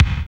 SWING BD 8.wav